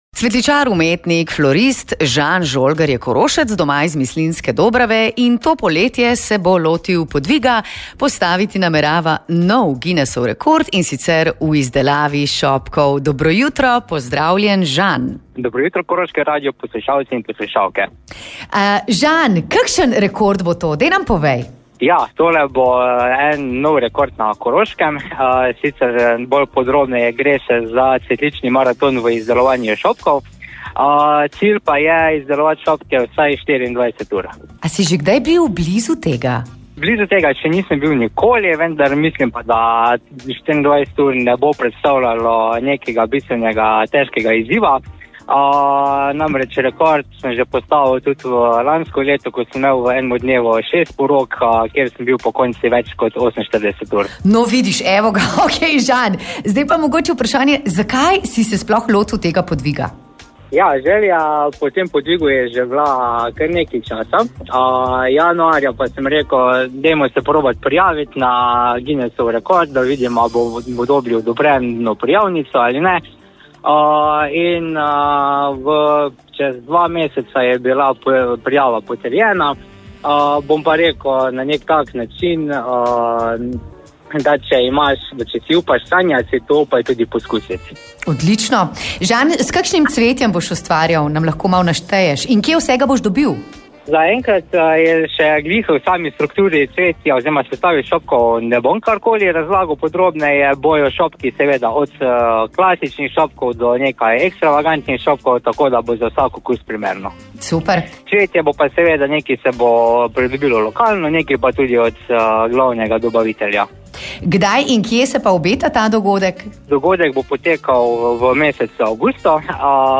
Ali se je že kdaj prej podajal podobnemu maratonskemu delu s cvetjem in kako je v dogodek vpletel še dobrodelnost? Intervju